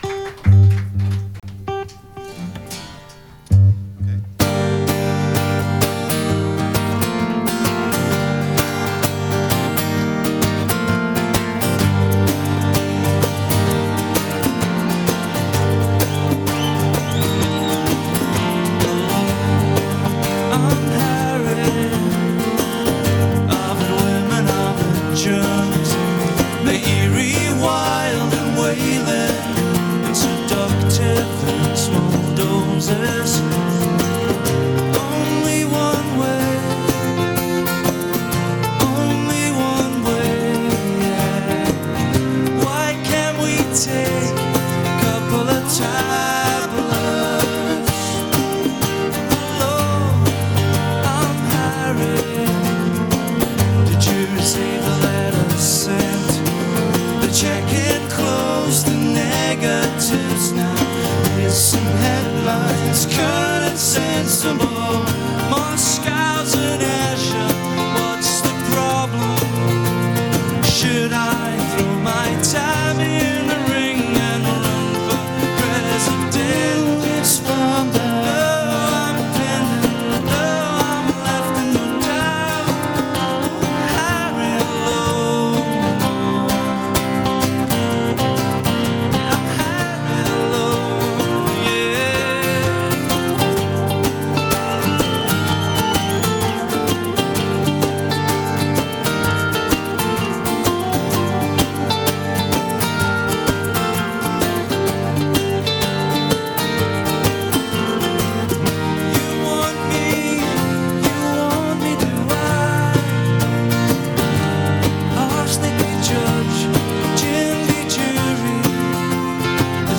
acoustic live at Fez, NYC, Summer 2004